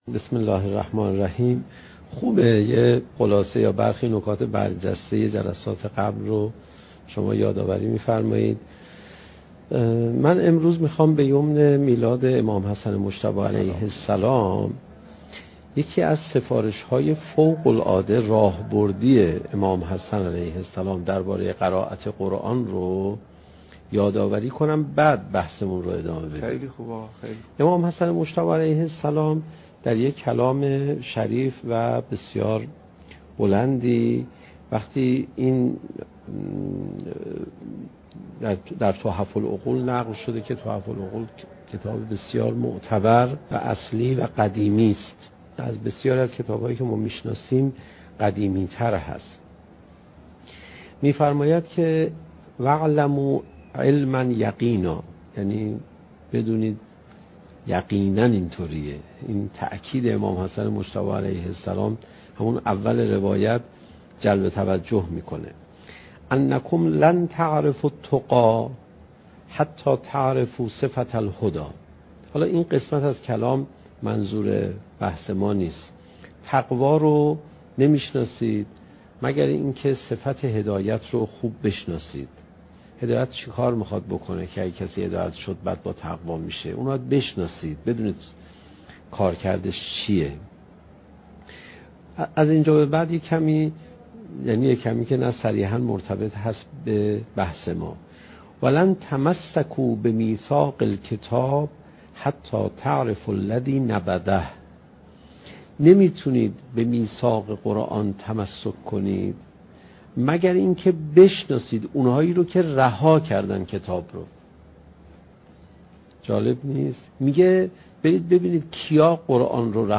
سخنرانی حجت الاسلام علیرضا پناهیان با موضوع "چگونه بهتر قرآن بخوانیم؟"؛ جلسه دوازدهم: "تاثیرگذاری زیبایی قرآن"